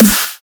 Snare 1 (Burst).wav